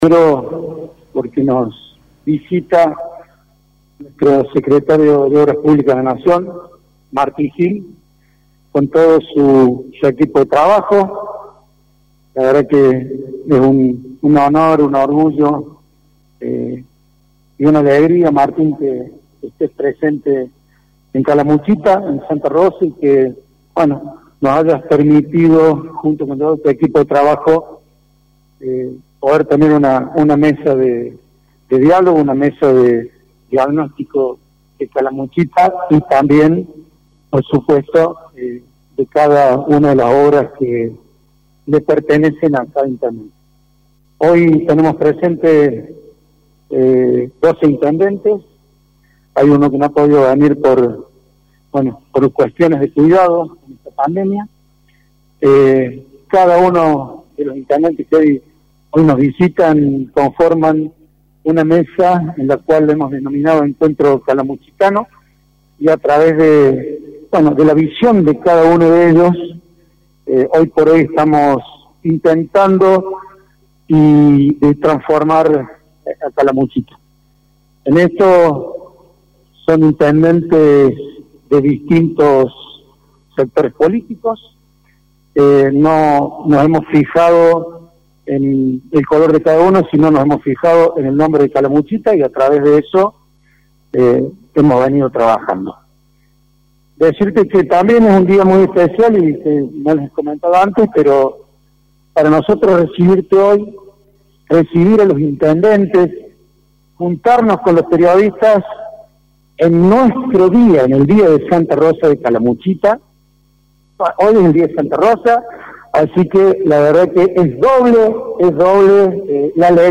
Lo anunció hoy el Secretario de Obras Públicas de la Nación , Martín Gill en conferencia de prensa. El monto del Programa Nacional será destinado a obras en la zona de la Costanera y en Villa Santarelli. Gill también resaltó las obras que se llevan adelante para la construcción de un hospital modular y anunció la llegada de fondos para diferentes localidades del Valle.